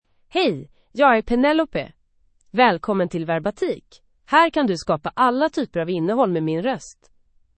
PenelopeFemale Swedish AI voice
Penelope is a female AI voice for Swedish (Sweden).
Voice sample
Listen to Penelope's female Swedish voice.
Penelope delivers clear pronunciation with authentic Sweden Swedish intonation, making your content sound professionally produced.